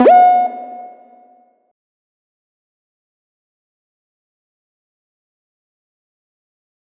Nightcrawler FX.wav